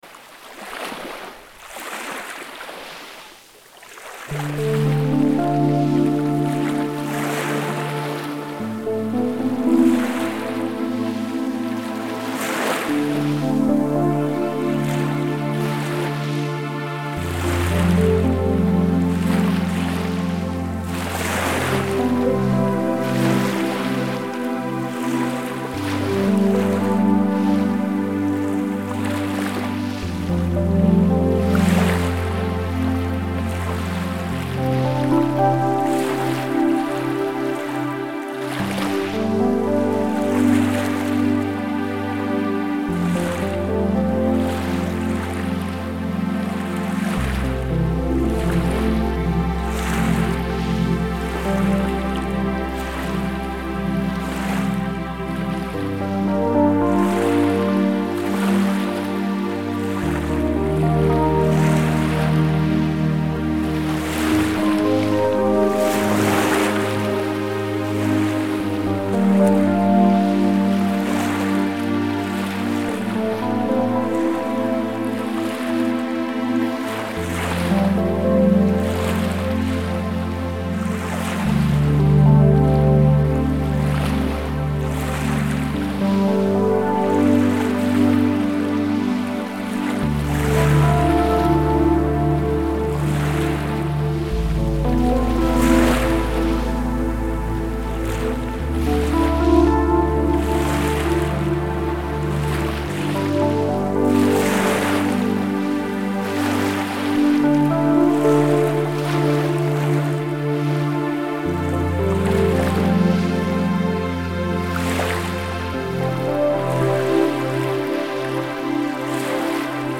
The track is seamlessly loopable for continuous playback.
Genres: Relaxing Music
Tempo: 112 bpm